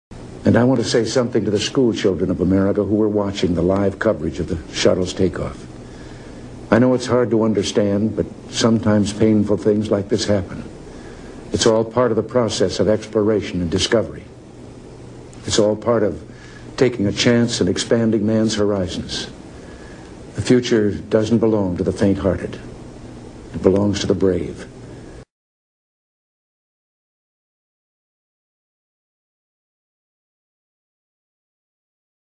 The Compact Disc contains speeches that should be played through large speakers in the concert hall.
05 Regan addresses school children